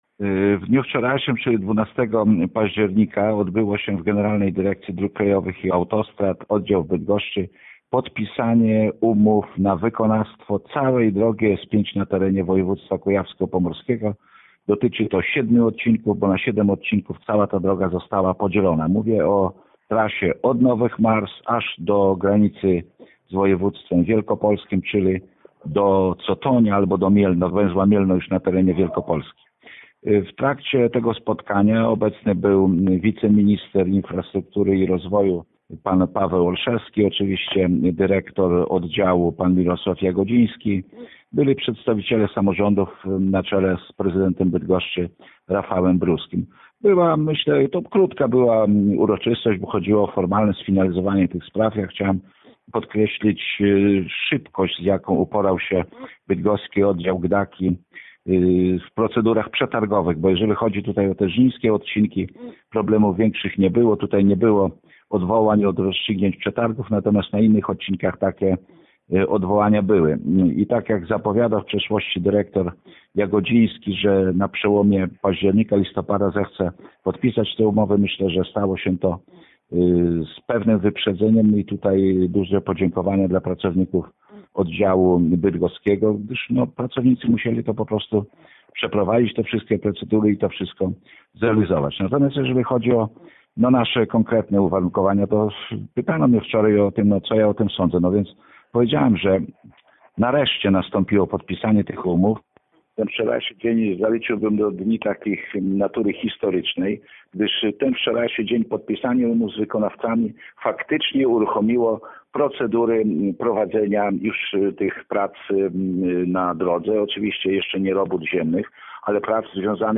Całość wywiadu ze Starostą Żnińskim Zbigniewem Jaszczukiem na temat podpisanych umów znajduje się poniżej.